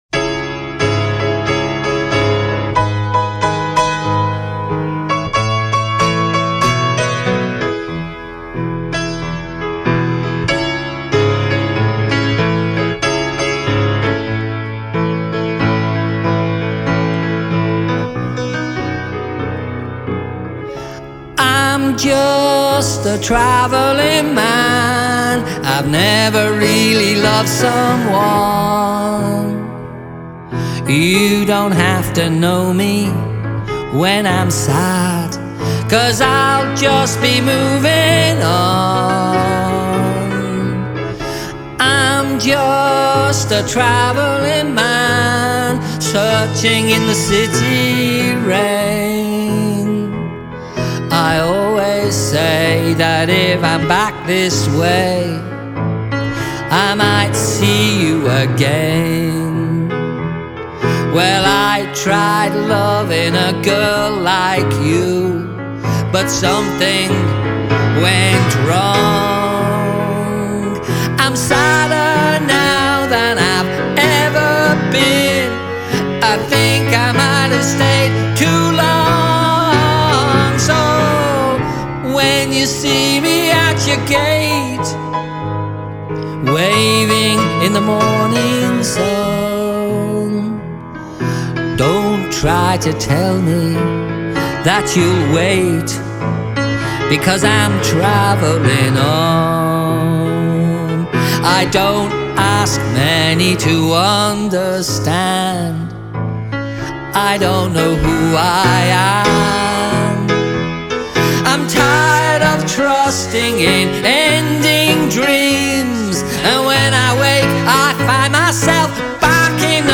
Piano and vocal